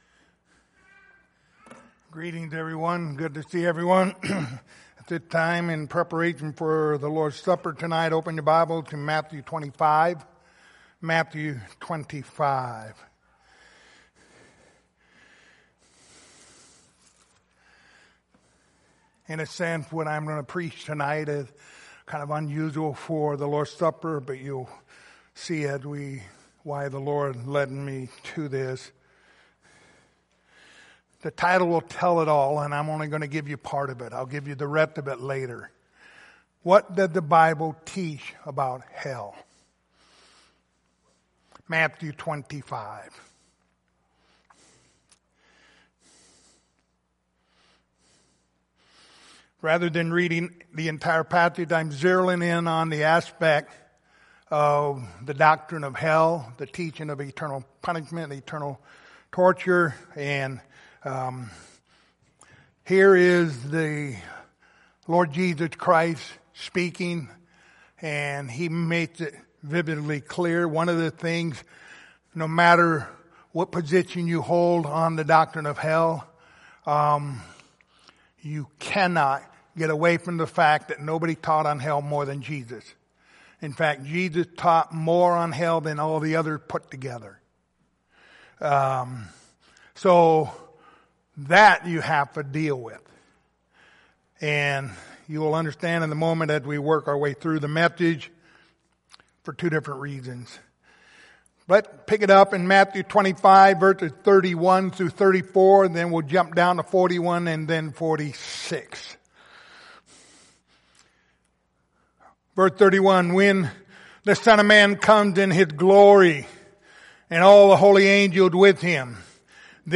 Matthew 25:31-46 Service Type: Lord's Supper Topics